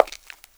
PAVEMENT 5.WAV